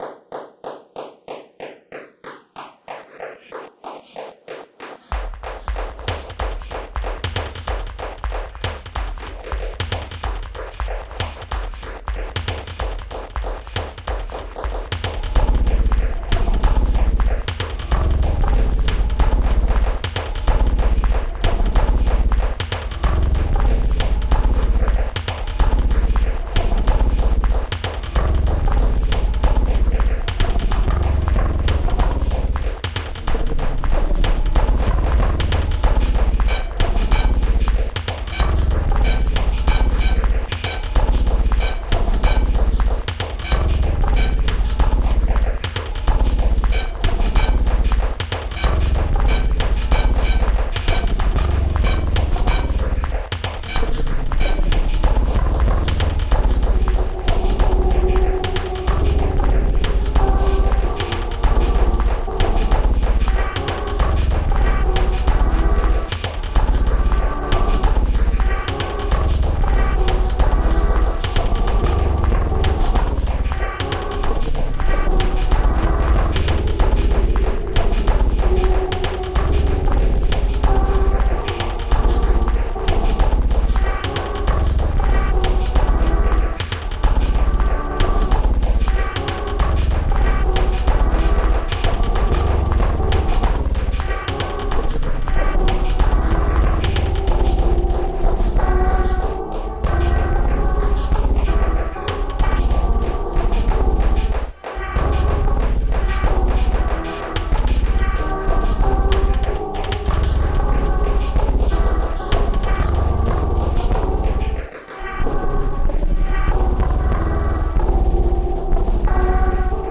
Composition: Remix